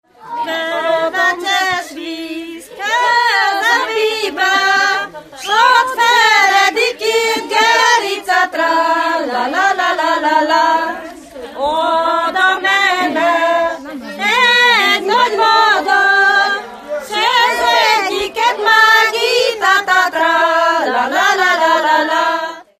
Moldva és Bukovina - Moldva - Alexandrina (Klézse)
Előadó: lakodalomra készülő szakácsasszonyok (sz. nincs), ének
Stílus: 7. Régies kisambitusú dallamok
Kadencia: b3 (2) 1